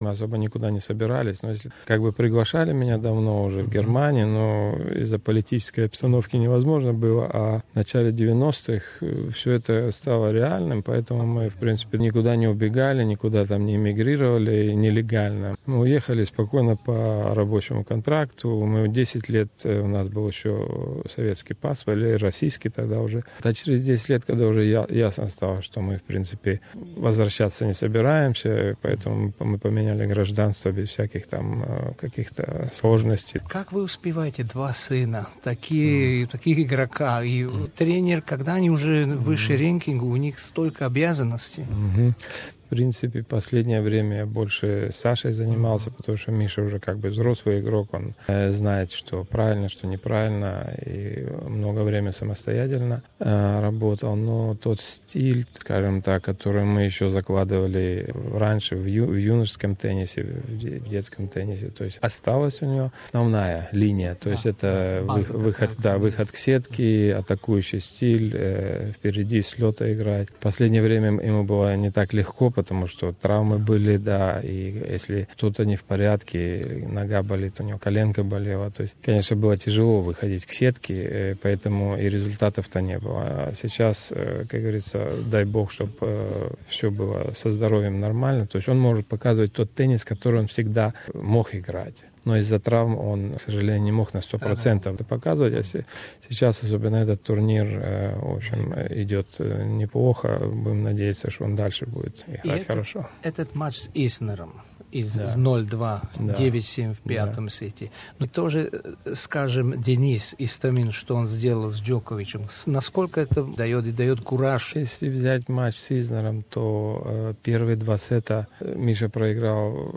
Мы расспросили Александра Зверева старшего об успехах сыновей, и также о том, почему семья переехала в Германию